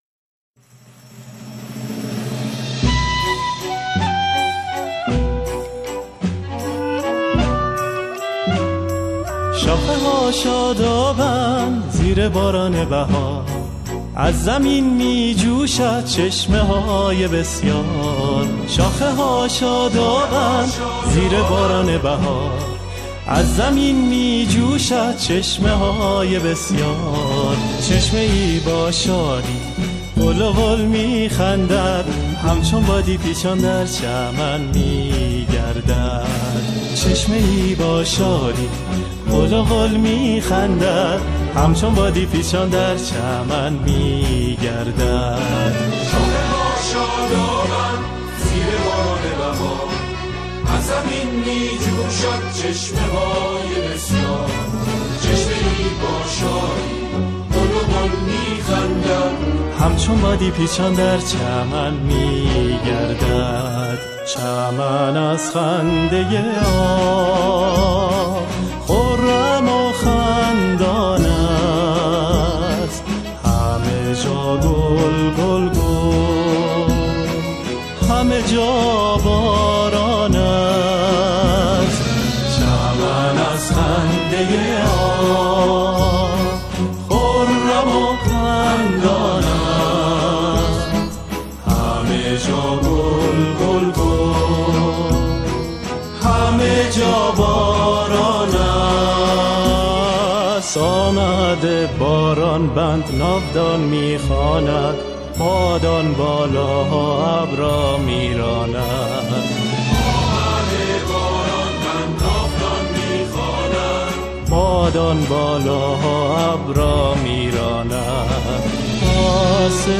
با کلام